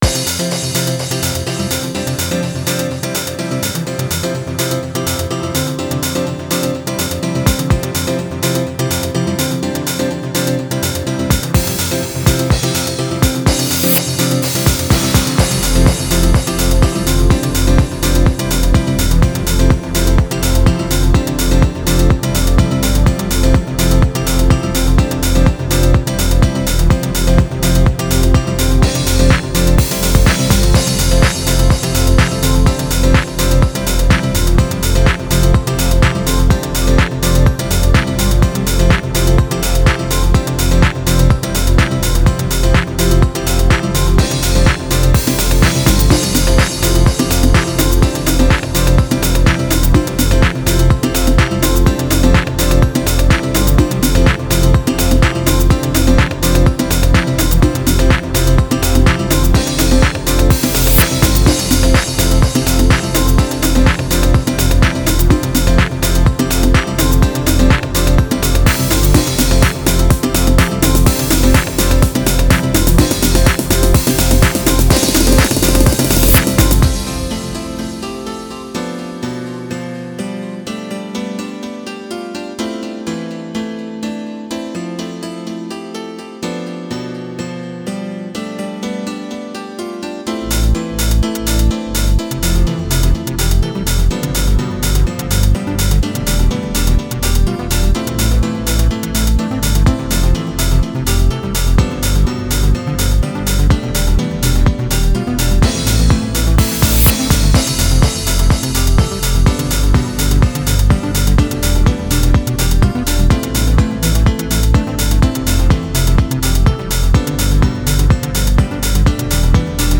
HOUSE（BPM１２５）
イメージ：空　ジャンル：Dream House、Piano House